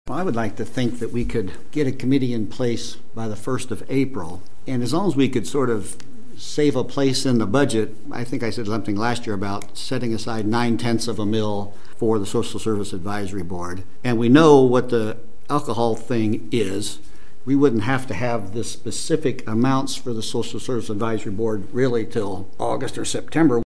That’s Commissioner Mark Hatesohl at Tuesday’s city commission work session.